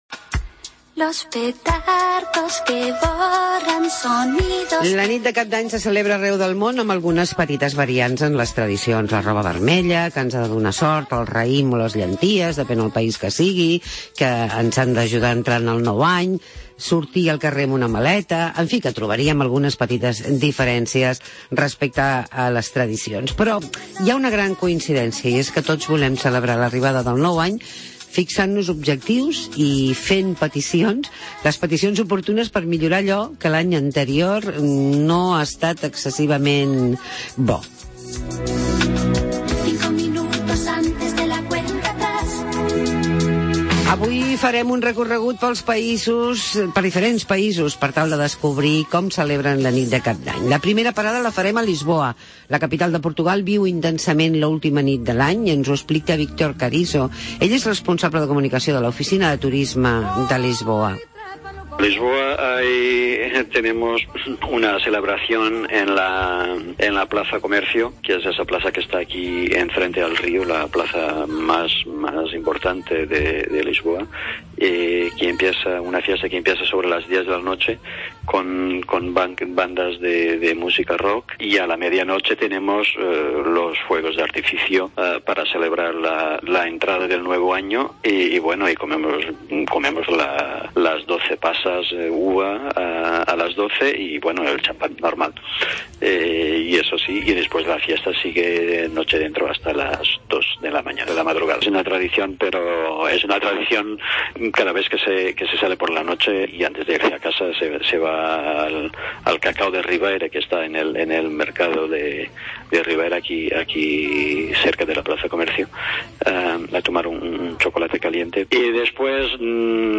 Reportaje Fin de año